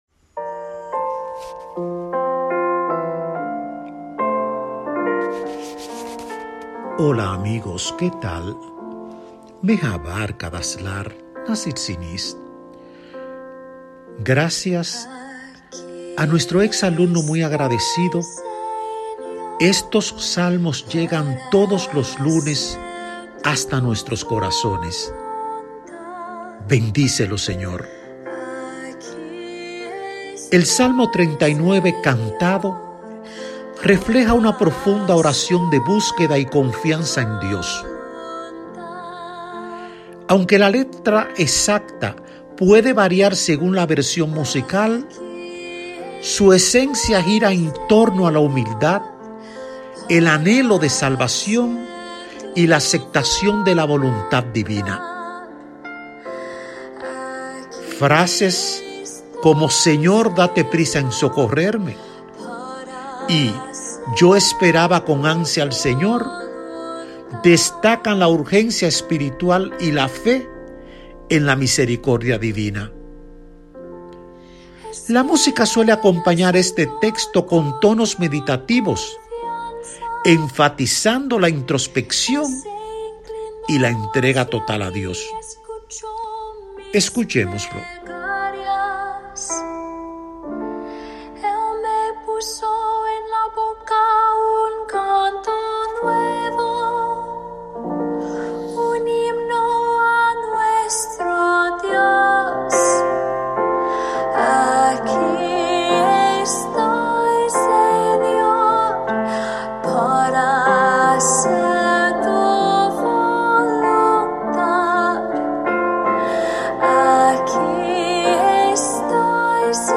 La música suele acompañar este texto con tonos meditativos, enfatizando la introspección y la entrega total a Dios.